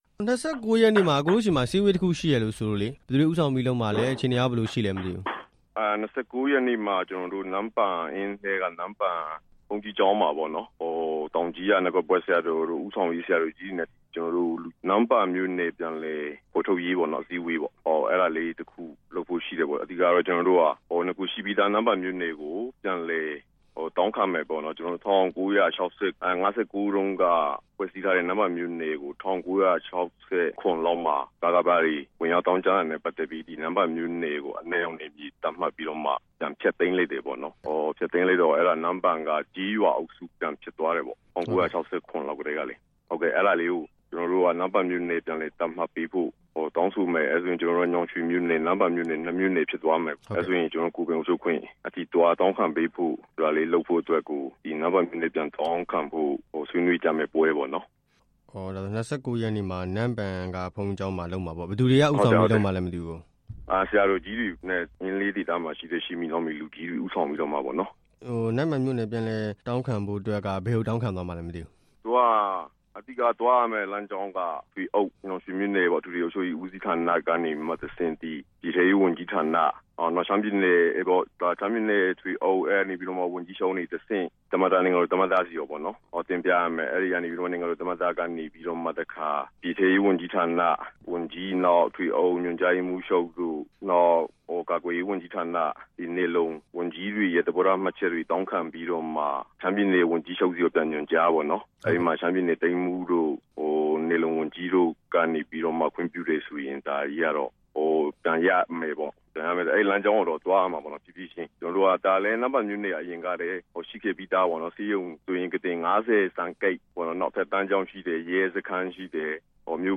အင်းတိုင်းရင်းသား ကိုယ်ပိုင်အုပ်ချုပ်ခွင့်အရေး မေးမြန်းချက်
မေးမြန်းခန်း